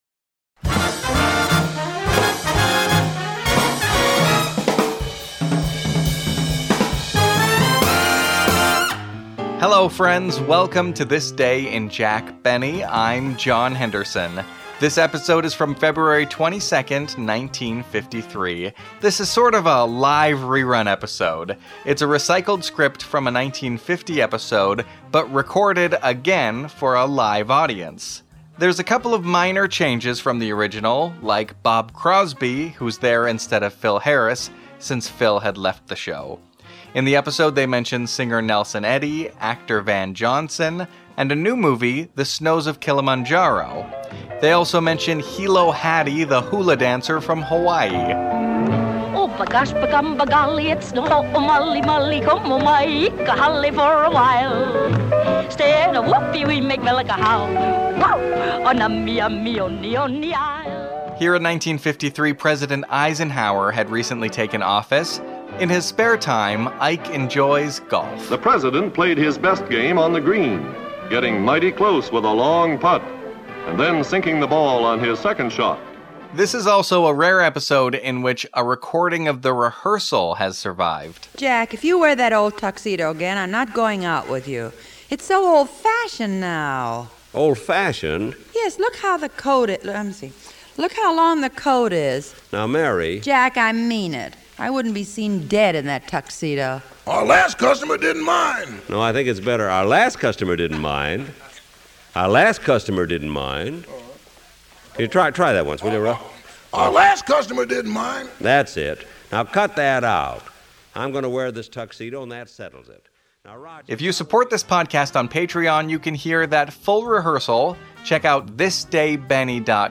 Kids Impersonate Jack Benny
February 22, 1953 – Kids of the Beverly Hill Beavers do their version of The Jack Benny Show! This is a re-used script from April 23, 1950.